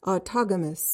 PRONUNCIATION:
(aw-TAH-guh-muhs)